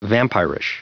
Prononciation du mot vampirish en anglais (fichier audio)
Prononciation du mot : vampirish